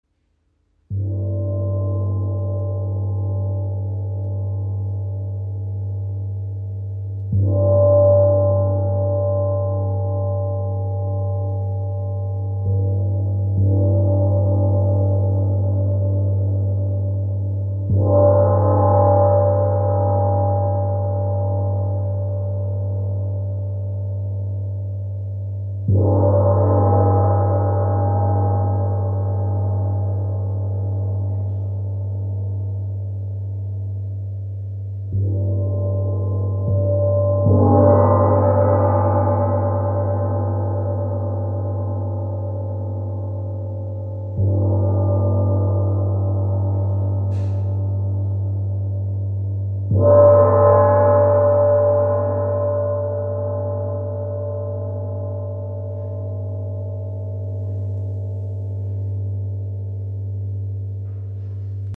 • Icon Kompakter Gong mit kraftvollem, resonantem Klang
Sideral Day Gong – Handgefertigtes Unikat aus Edelstahl, gestimmt auf 194,71 Hz (G2) nach der Kosmischen Oktave. Ideal für Meditation und Klangheilung.
Sein klarer, resonanter Klang wirkt beruhigend und zentrierend, unterstützt Meditation, fördert innere Balance und hilft, sich mit den natürlichen Zyklen des Lebens in Einklang zu bringen.
• Frequenz: 194,71 Hz (G2)